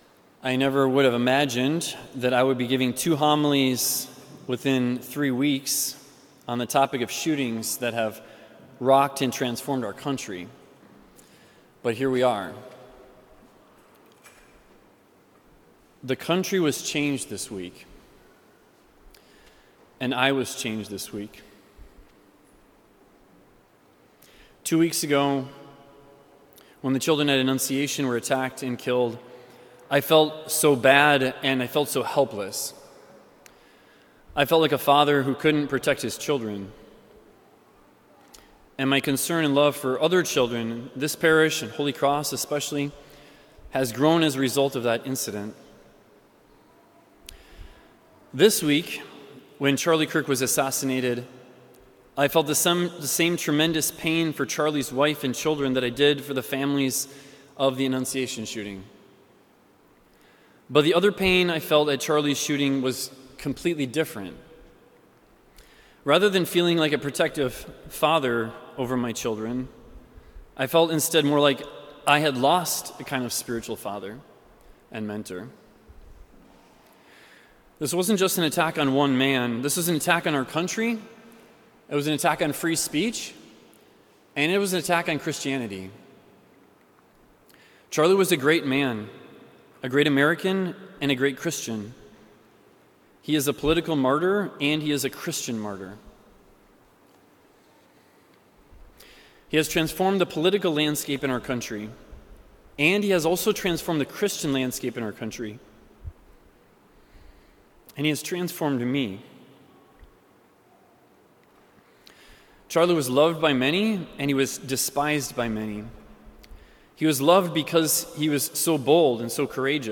Heartfelt Homily on the Death of Charlie Kirk